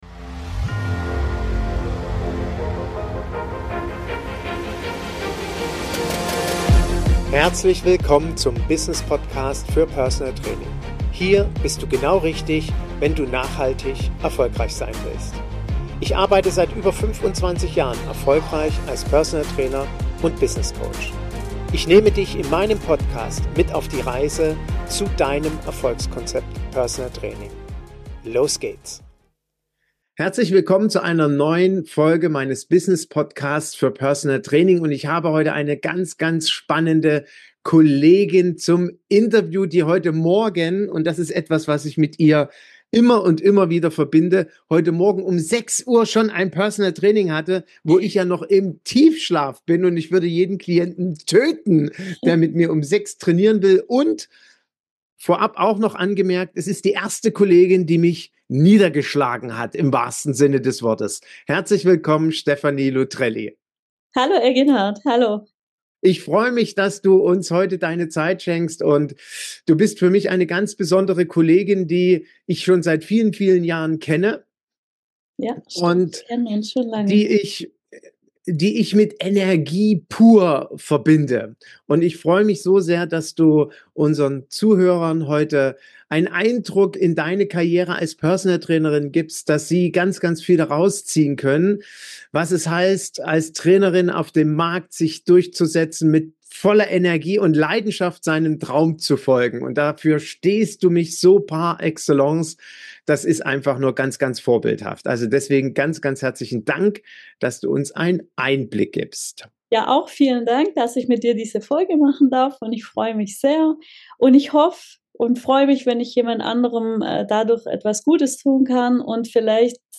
Im Interview